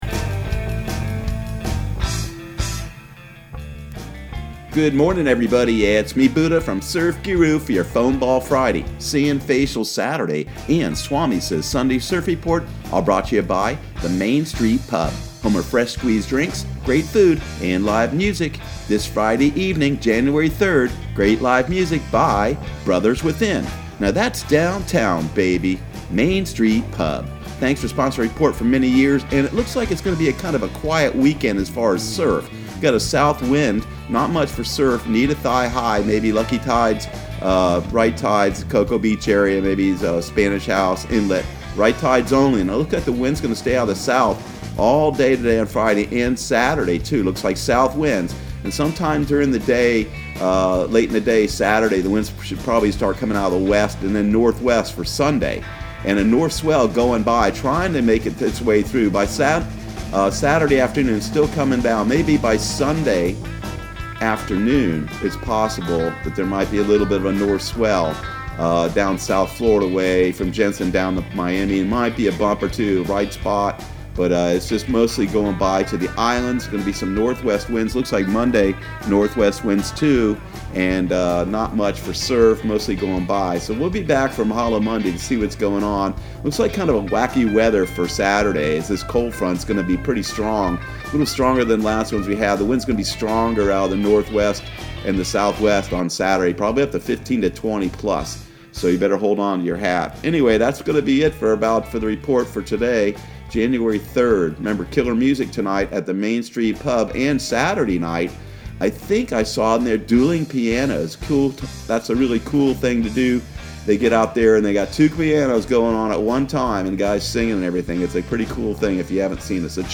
Surf Guru Surf Report and Forecast 01/03/2020 Audio surf report and surf forecast on January 03 for Central Florida and the Southeast.